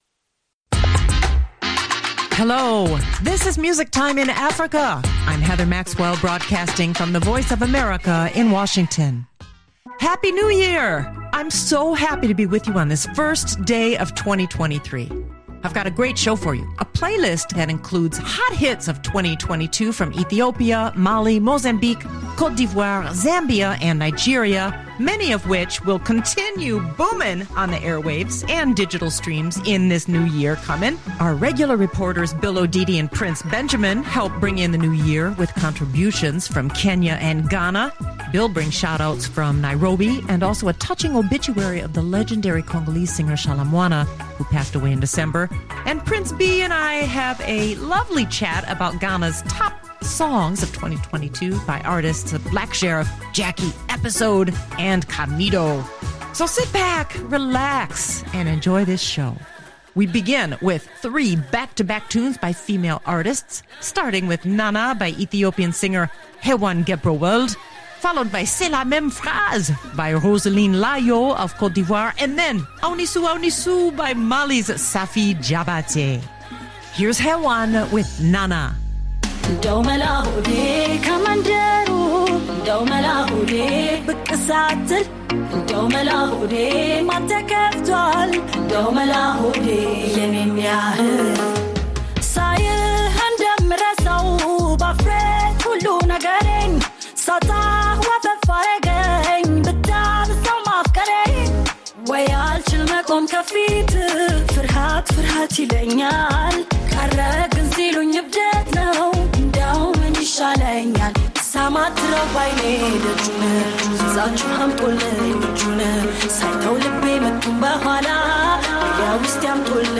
live from a concert in Ibadan
contemporary Nigerian Highlife band